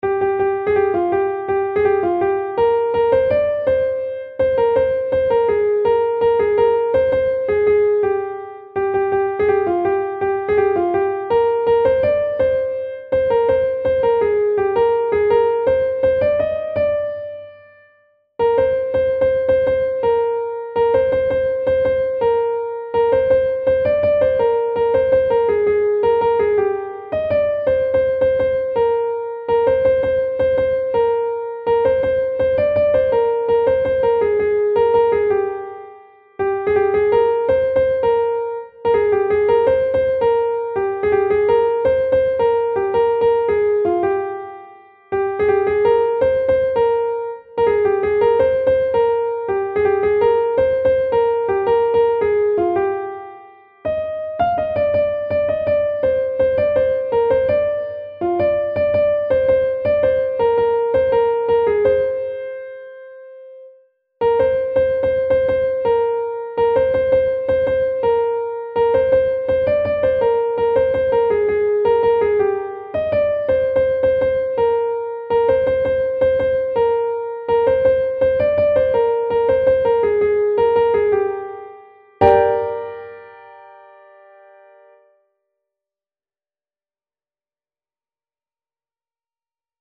نت کیبورد